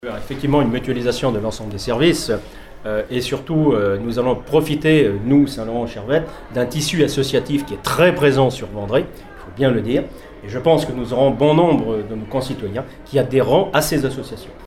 La mutualisation, une bonne chose pour le maire de Chervettes Daniel Rousseau :
Les élus se sont exprimés hier soir à l’occasion des vœux de la CdC Aunis Sud qui se sont tenus symboliquement à La Devise, devant 200 invités et un parterre d’élus locaux.